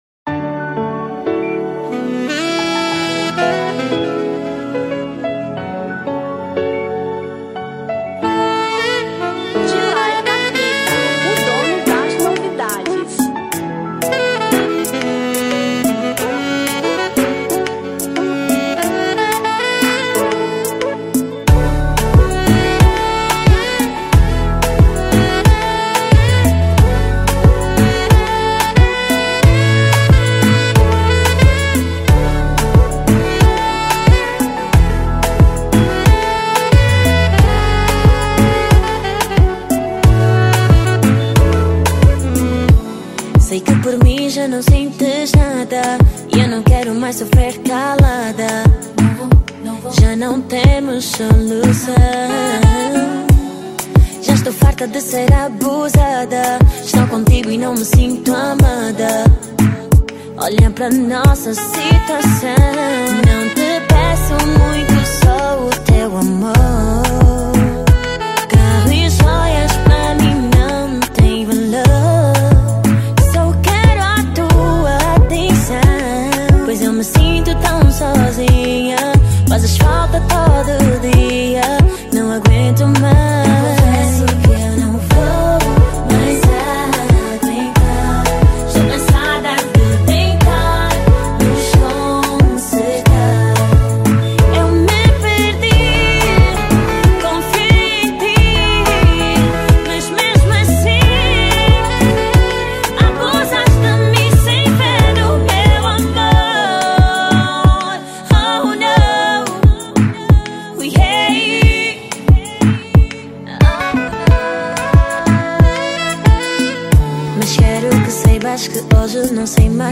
Kizomba 2012